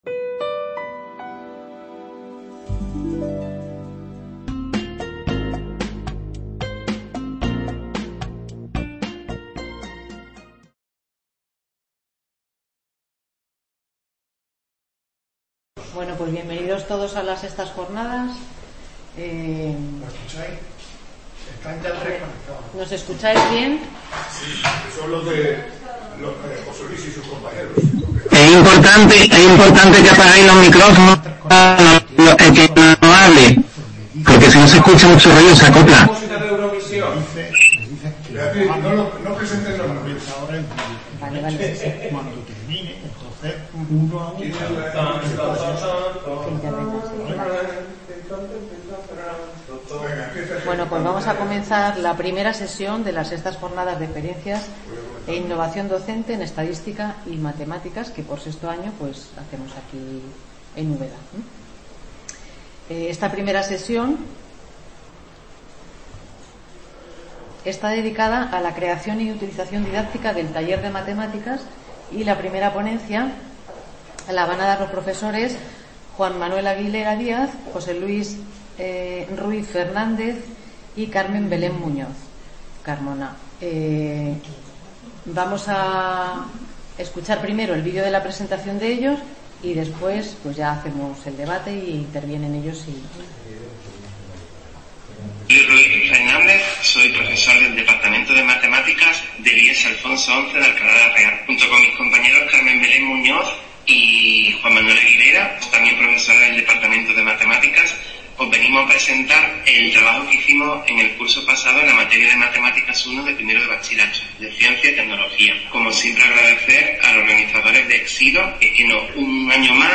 Ponencia
Estas jornadas se desarrollan en el Centro Asociado de la UNED de Úbeda y pretenden ser un punto de encuentro para compartir experiencias educativas reales en Estadística y/o Matemáticas que se enmarquen en el ámbito cotidiano de la docencia en los diferentes niveles de la educación Matemática, aunque está abierto a docentes de otras materias.